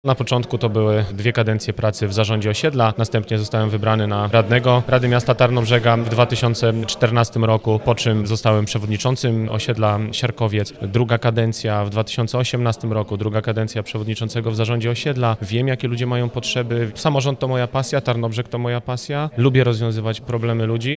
Podczas konwencji wyborczej zorganizowanej w Tarnobrzeskim Domu Kultury przedstawiono sylwetki każdego z nich.